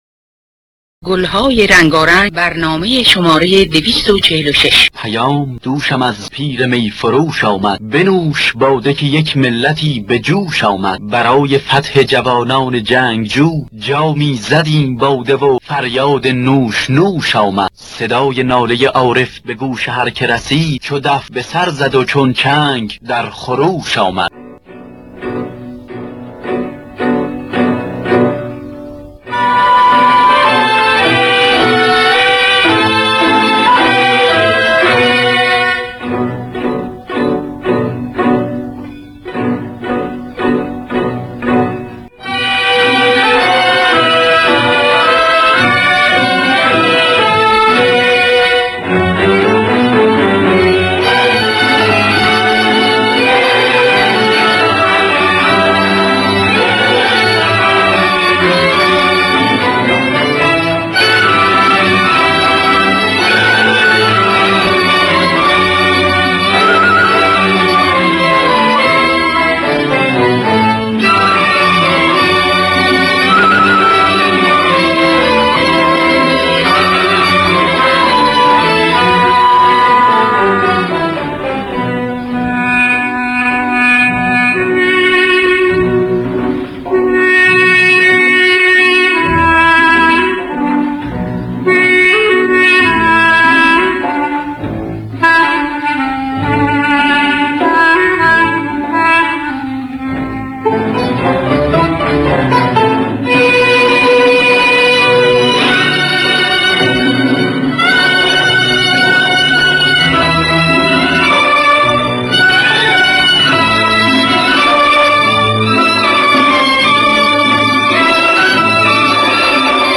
در دستگاه دشتی